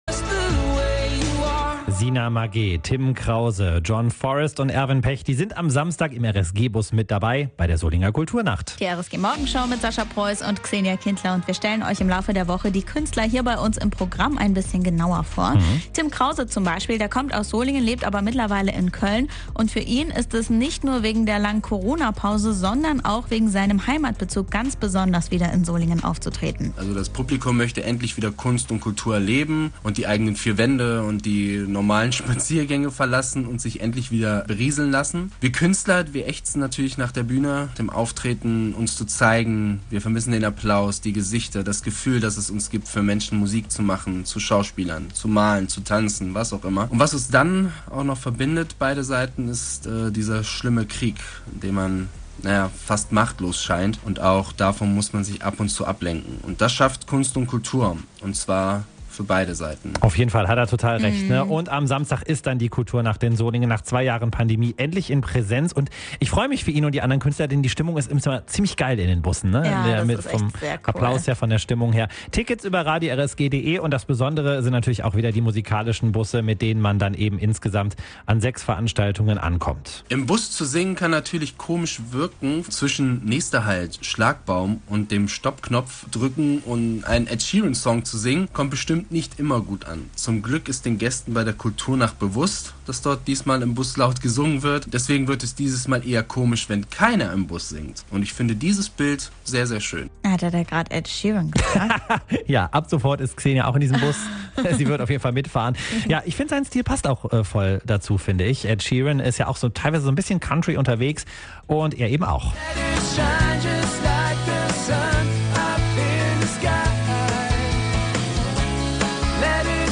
Kultur Nacht Solingen